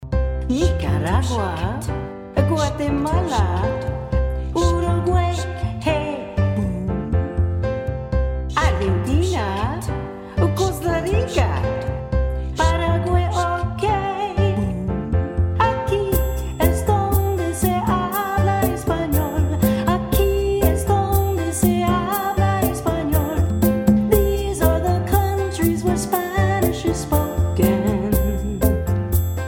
Vocal and
▪ The full vocal track.